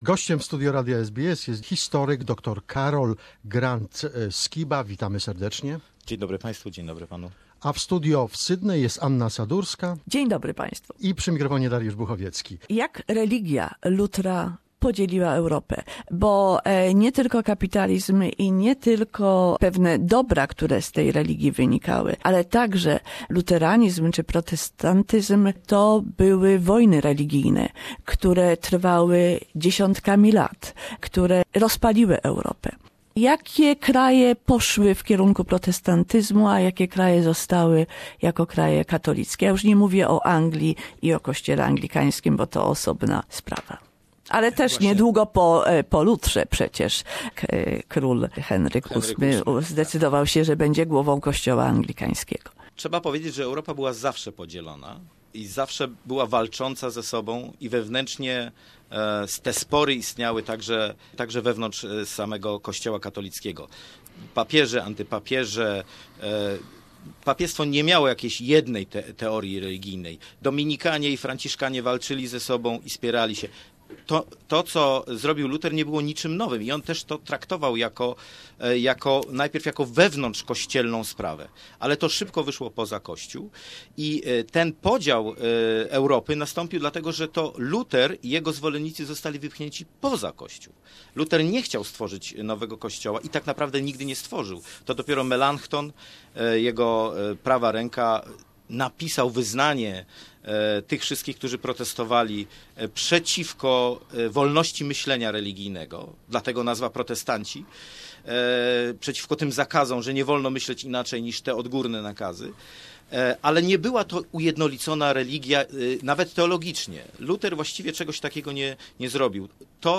Martin Luther left behind a rich legacy of protest and reform. Conversation